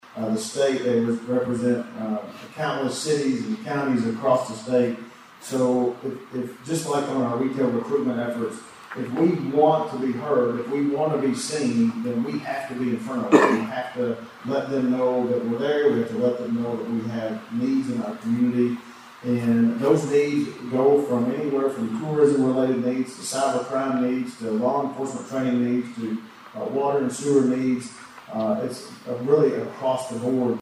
Local leaders are actively advocating in Frankfort and Washington, D.C., to secure funding for economic growth, veteran support, and infrastructure projects, which was one of the topics discussed at last week’s State of the Cities and County event.